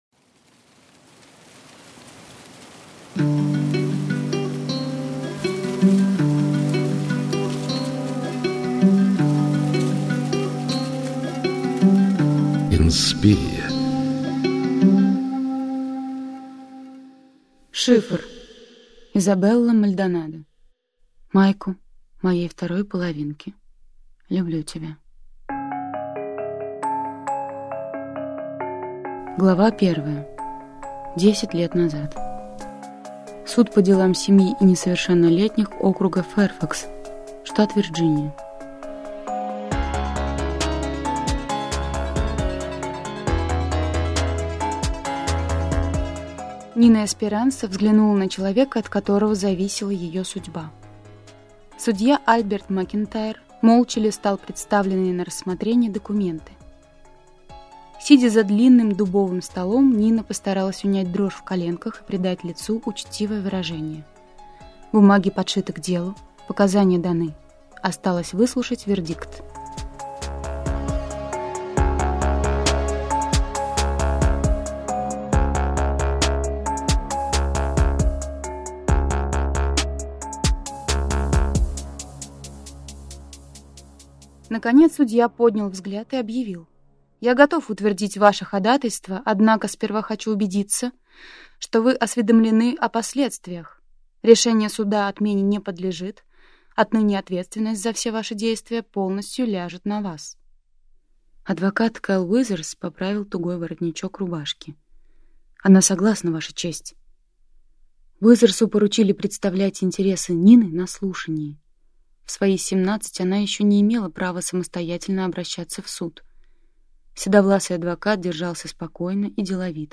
Аудиокнига Шифр | Библиотека аудиокниг